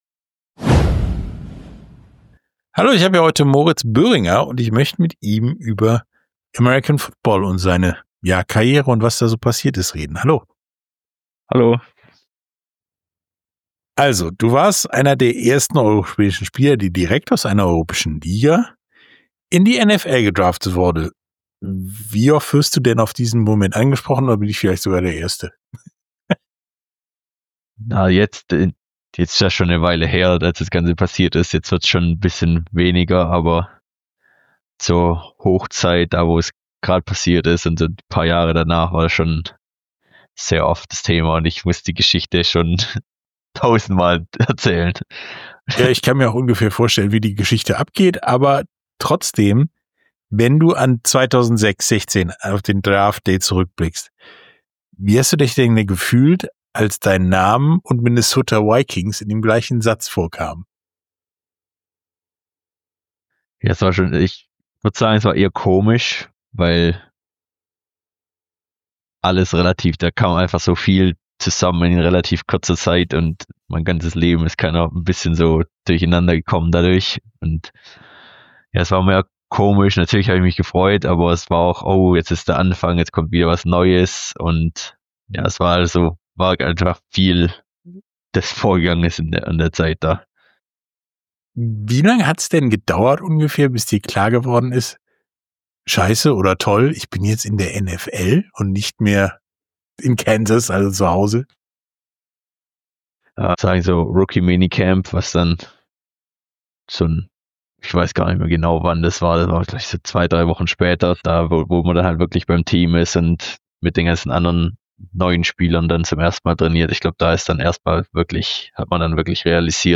Sportstunde - Interview Moritz Böhringer, American Football (Minnesota Vikings) ~ Sportstunde - Interviews in voller Länge Podcast